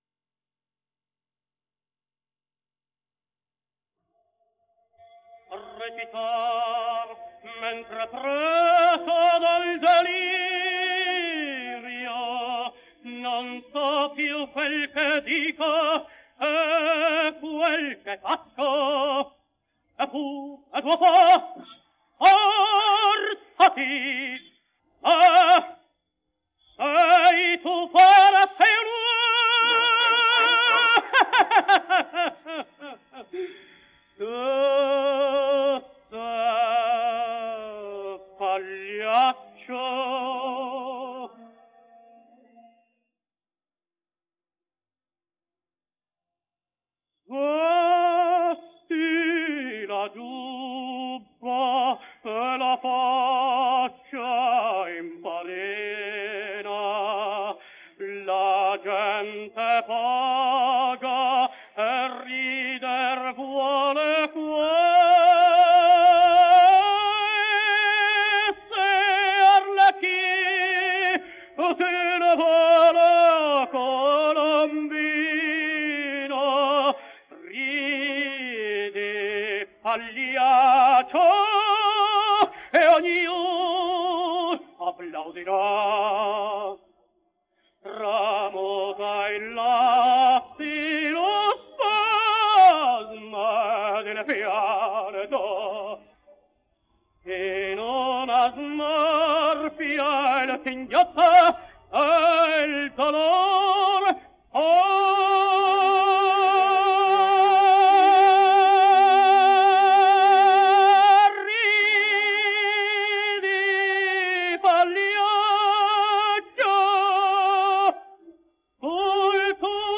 denoised_vocals.wav